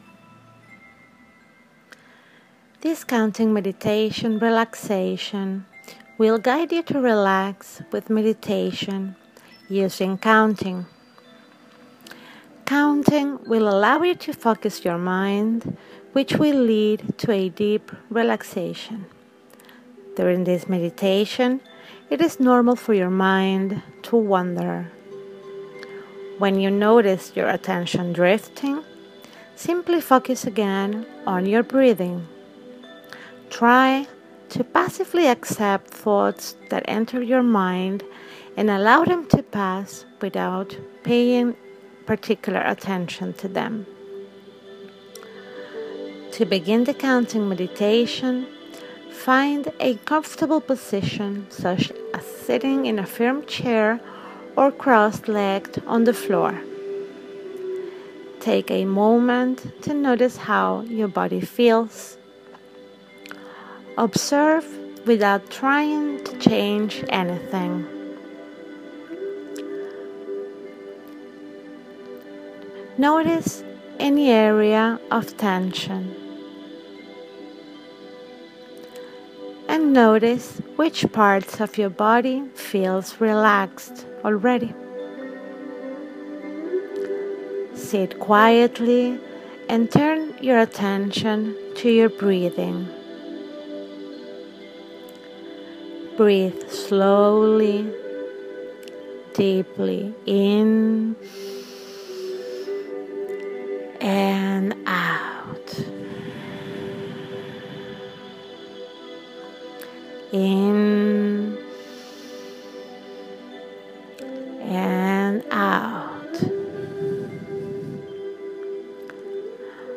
Guided Meditation: Breath counting meditation for relaxation